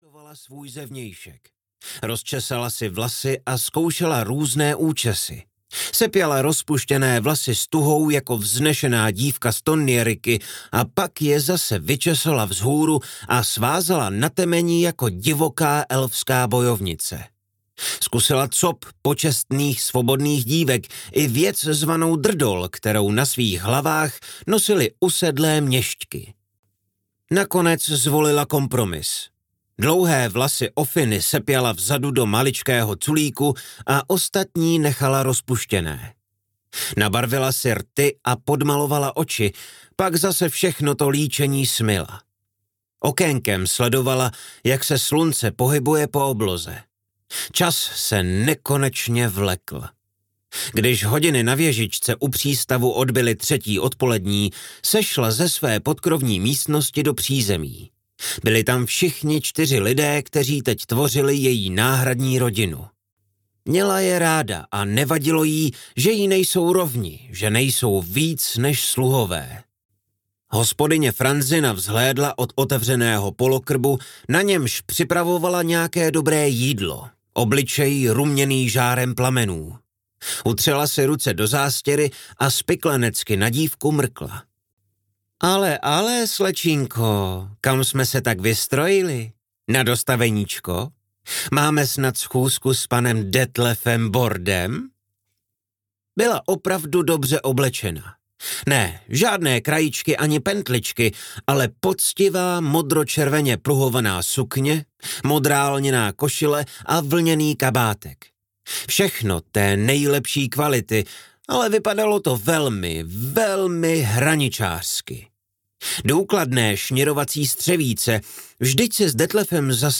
Šílený les audiokniha
Ukázka z knihy